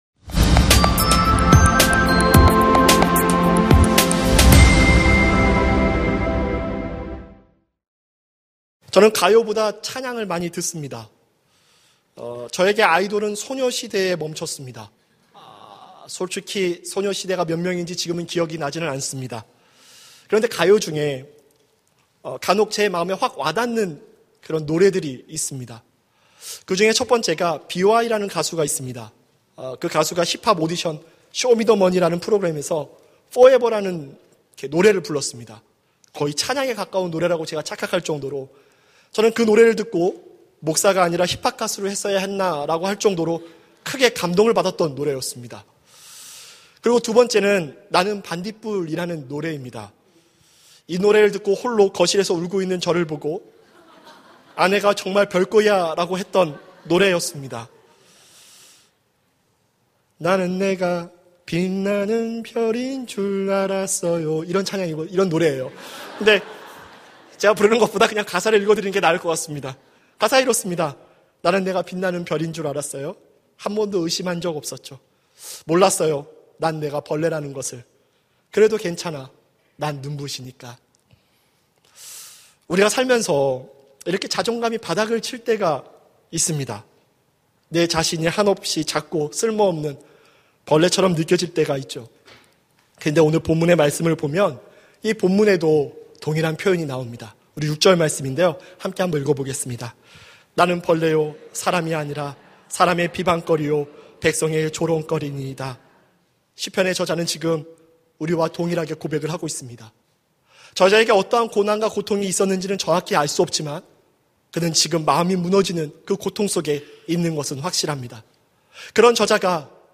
설교 : 금요심야기도회 (수지채플) 어찌하여 나를 버리십니까?